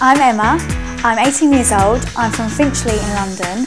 to hear Emma introduce herself 8 years ago click here: